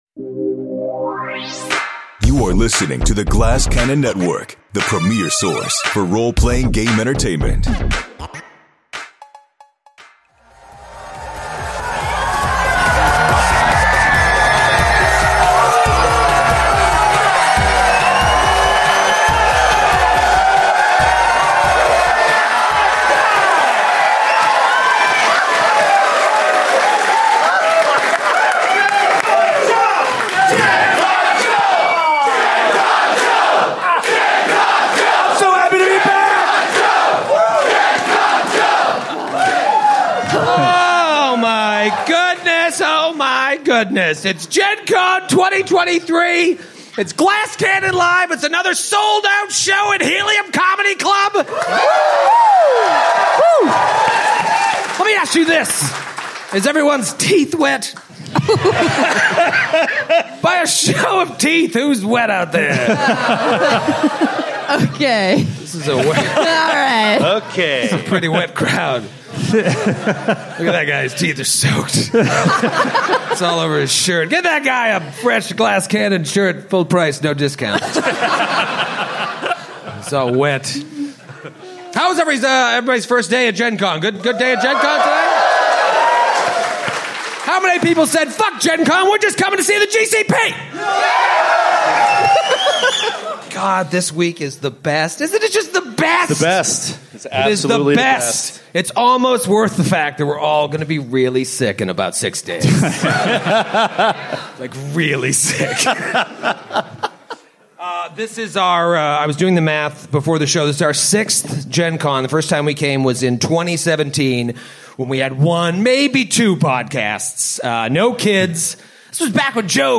After finally gaining an audience with the Mad Poet and learning terrible truths about Lowls and themselves, the heroes' boat journey comes to an end. There's nothing like an Indianapolis Helium Comedy Club show during Gen Con, and this off-the-wall show did not disappoint!